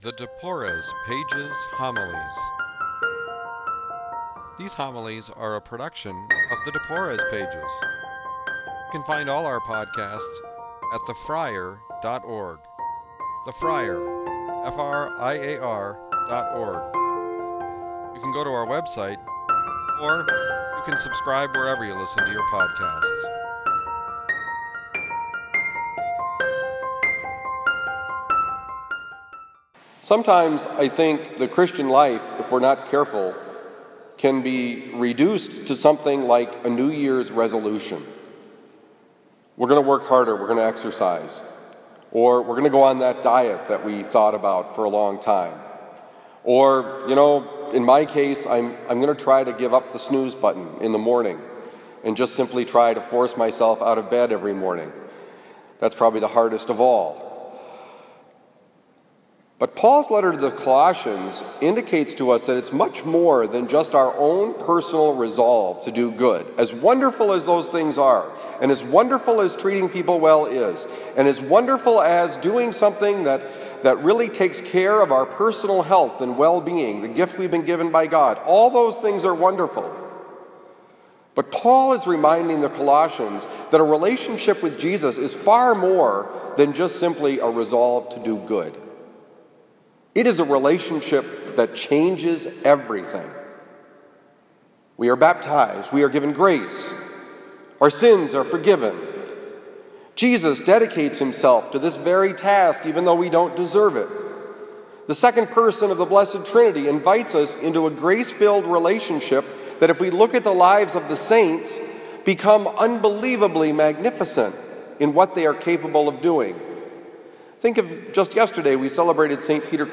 Homily for the 23rd Tuesday in Ordinary time, September 10, 2019, at Christian Brothers College High School, Town and Country, Missouri.